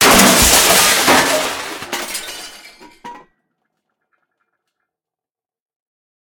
combat / armor / break.ogg
break.ogg